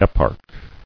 [ep·arch]